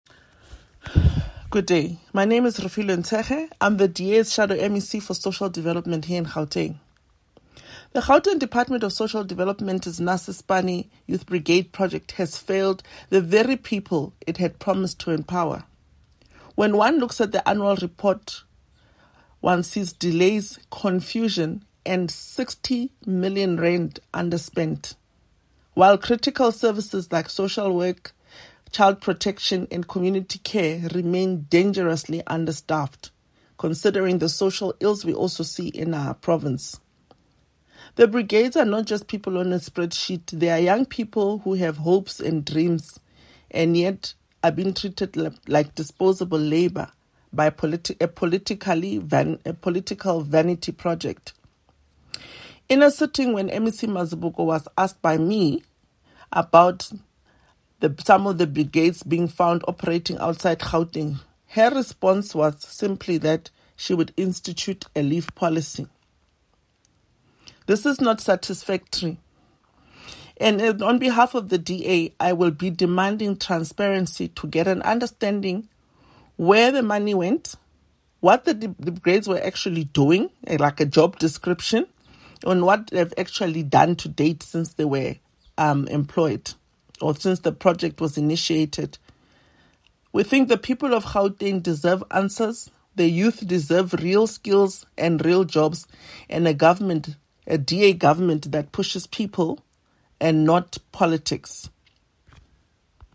soundbite by Refiloe Nt’sekhe MPL